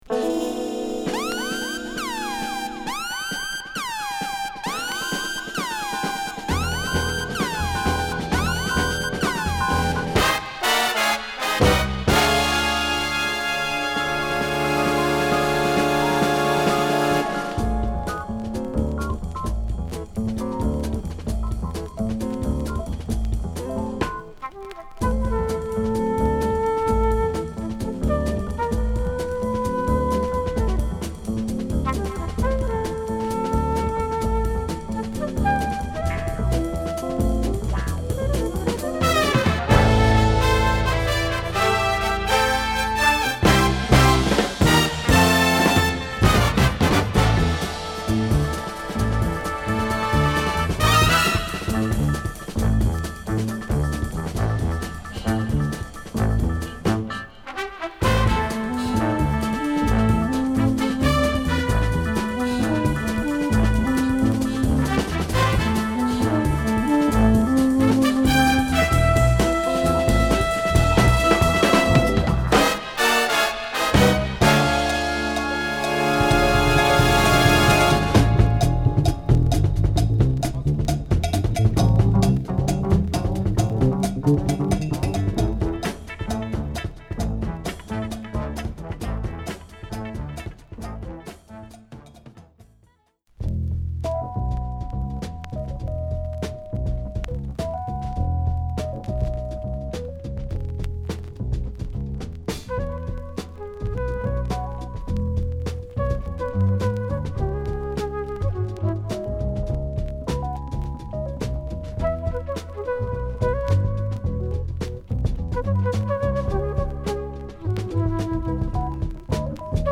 シネマティックな物からジャズ・ファンクまで